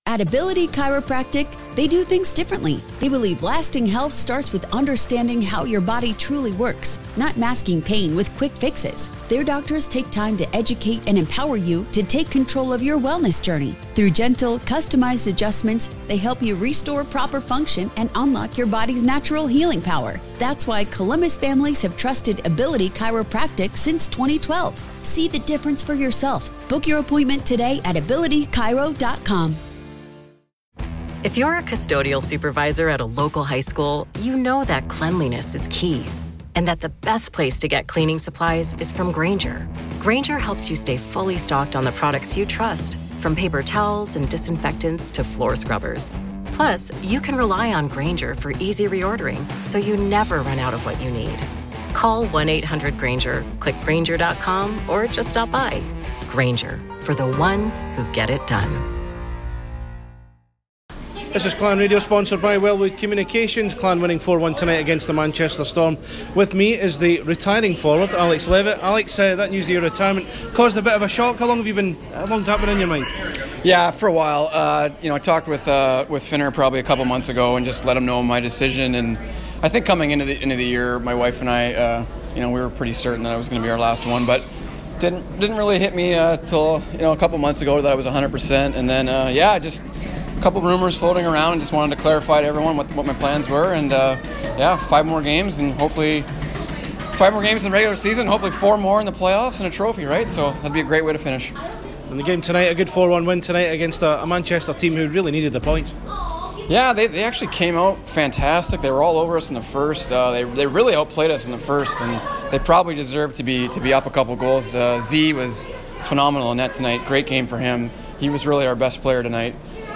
POST MATCH